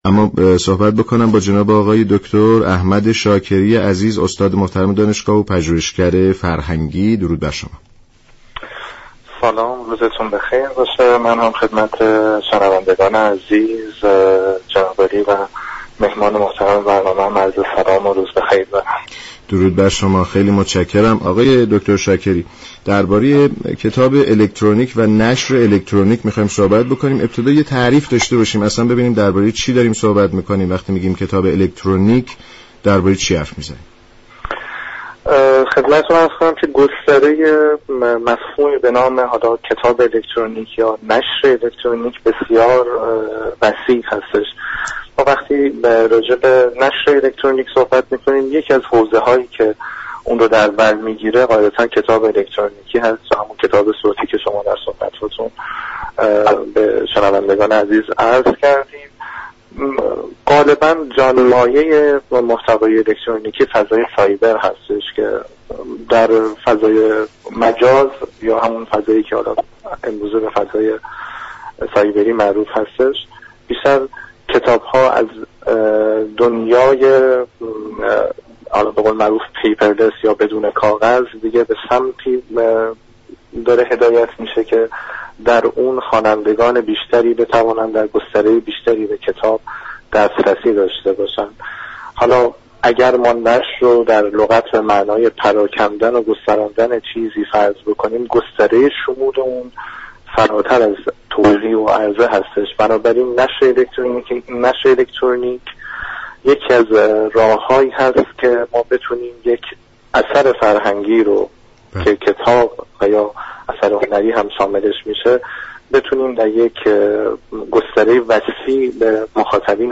در گفت و گو با برنامه كافه هنر رادیو ایران به بحث نشر الكترونیك پرداخت
كافه هنر رادیو ایران شنبه تا چهارشنبه هر هفته ساعت 15:50 از رادیو ایران پخش می شود.